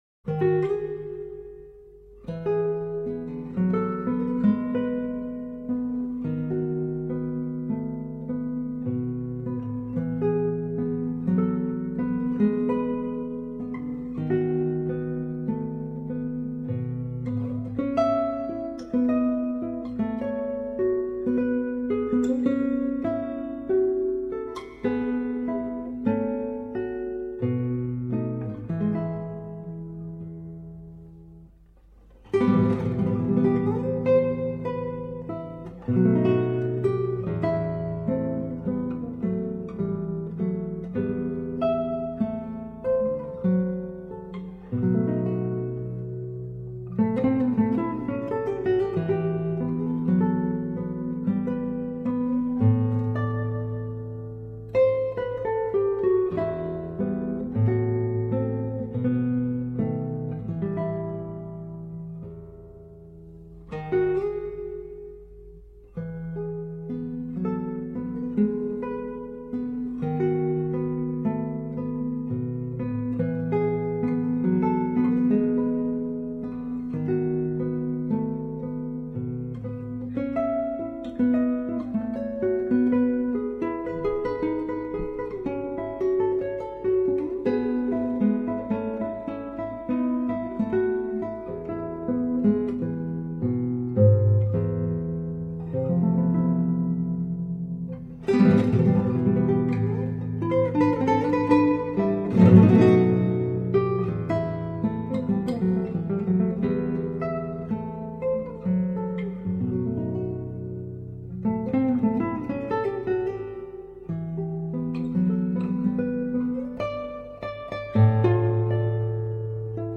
0200-吉他名曲泪水.mp3